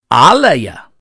楞伽经梵音词汇读诵001-010